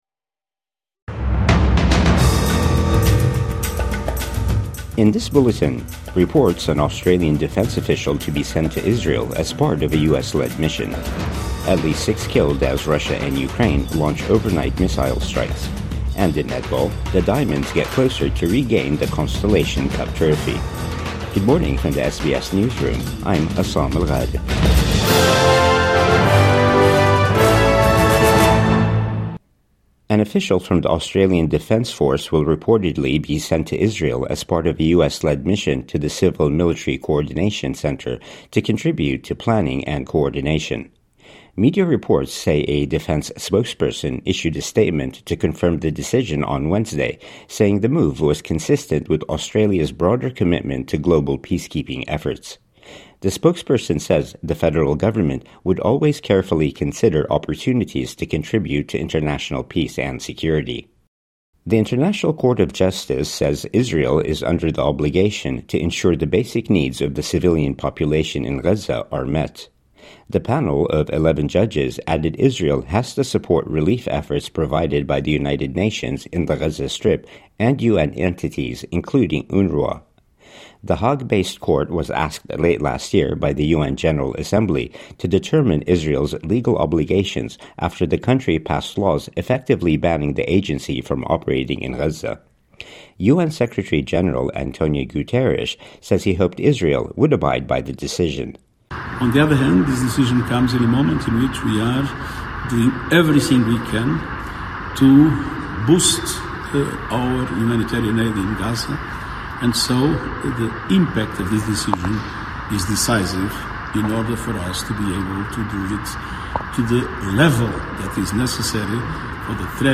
ADF official may be sent to Israel as part of US-led mission | Morning News Bulletin 23 October 2025